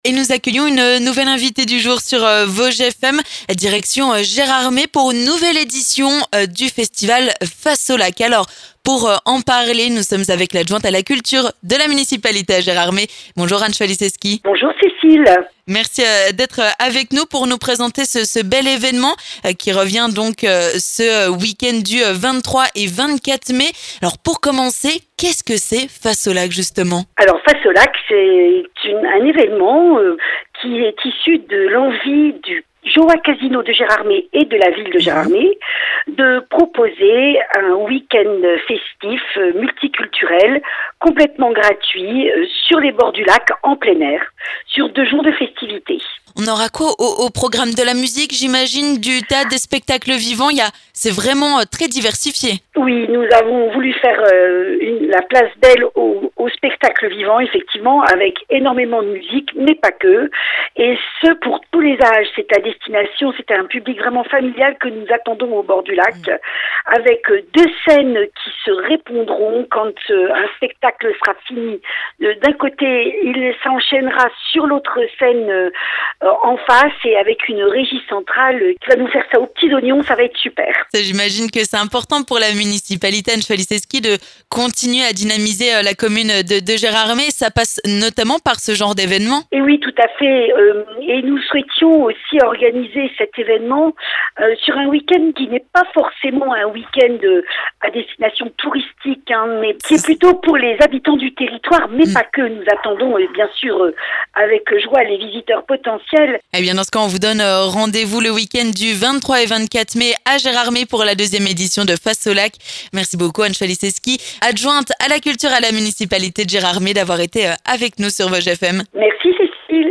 L'invité du jour
Concerts, spectacles et ateliers seront au programme au bord et sur le lac ! Alors pour ne rien manquer, nous sommes allés à la rencontre de l'adjointe au maire de Gérardmer en charge de la culture, Anne Chwaliszewski.